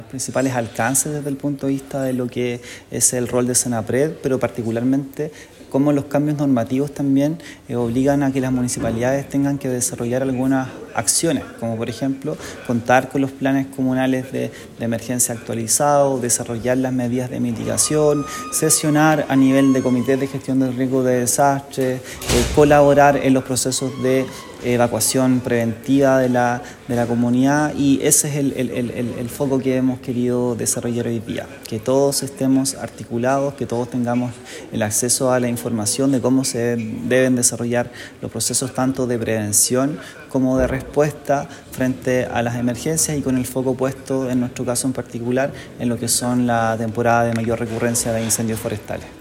González también destacó que el trabajo del SENAPRED incluye verificar la correcta implementación de los planes de emergencia a nivel comunal y apoyar la formación de capacidades en cada municipio.